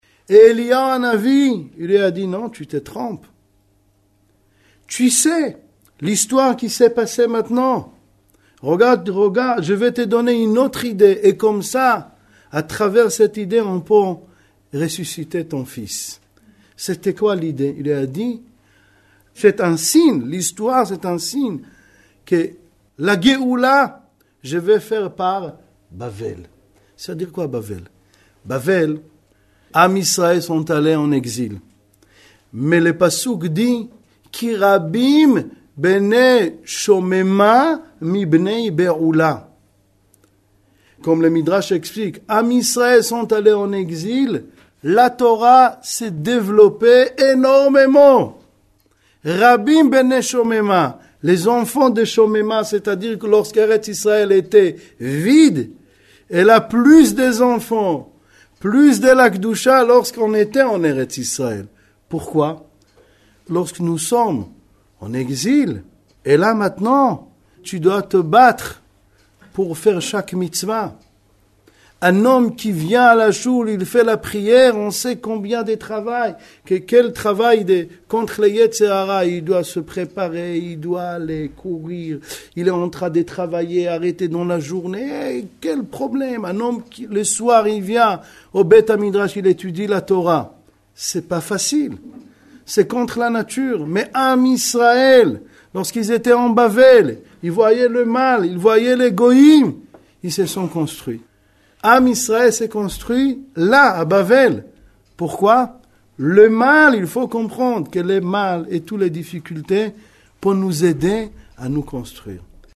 01:08:39 Exposé donné le Motsé Shabbath Shemoth 24 Tévetn 5767 – 13 janvier 2007 au Igoud ‘Harédi à Paris.